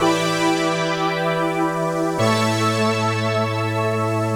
AM_VictorPad_110-E.wav